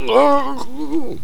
drown.ogg